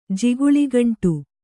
♪ jiguḷi gaṇṭ